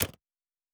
pgs/Assets/Audio/Sci-Fi Sounds/Interface/Click 2.wav
Click 2.wav